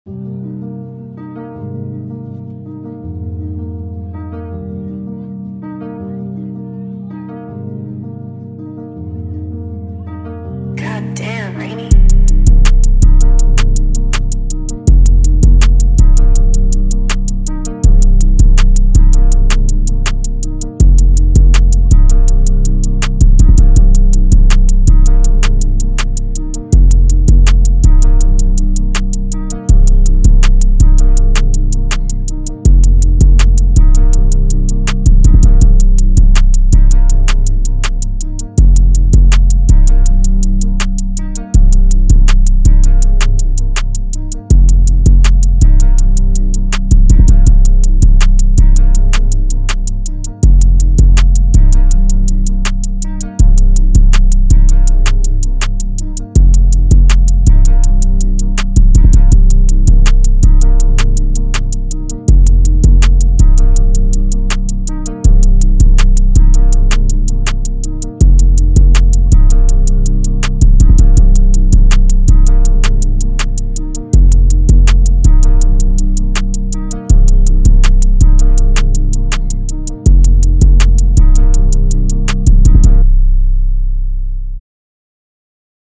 162BPM (RAP/ALTERNATIVE RAP)